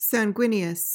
PRONUNCIATION:
(sang-GWIN-ee-uhs)